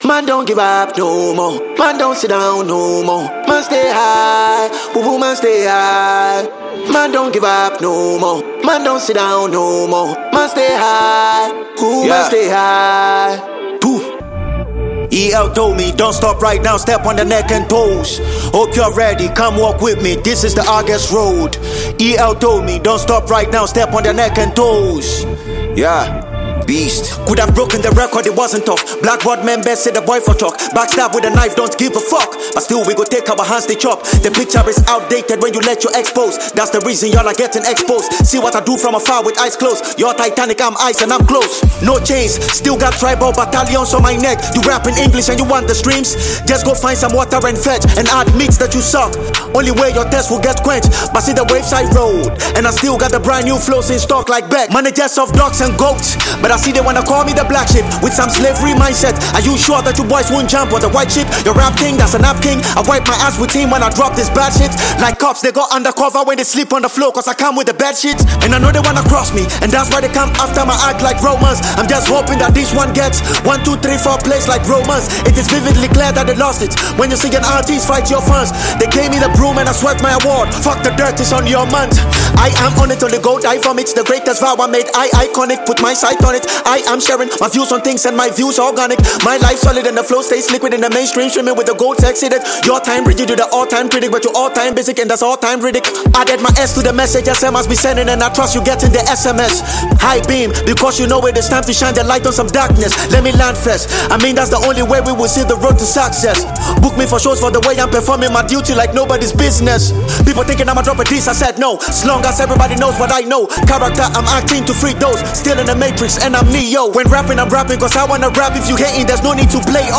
Ghanaian rapper and songwriter
freestyle